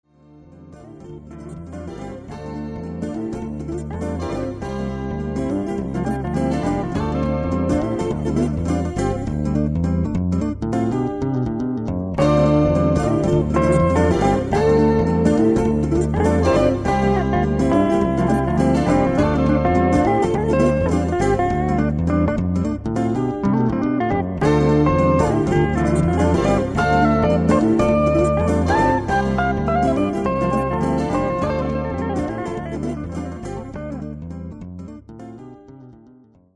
A jazzy tune that develops into a loop.